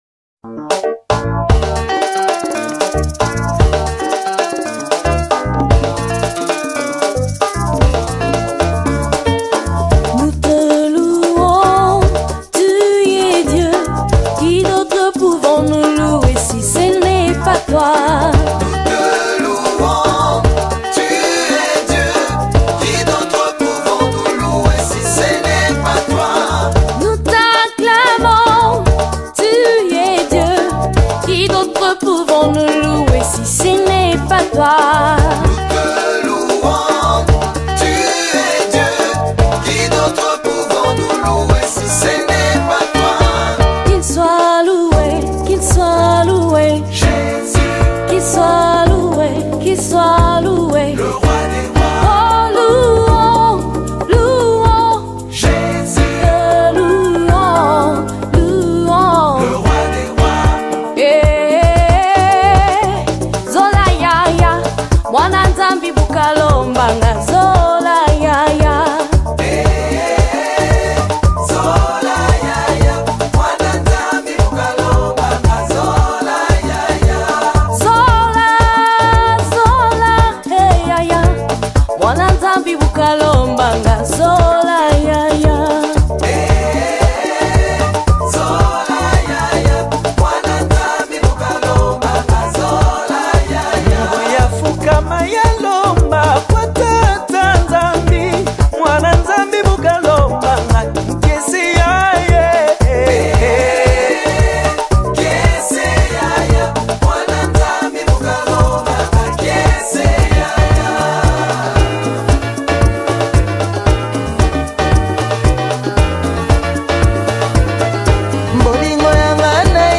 A Unique Afrobeat Fusion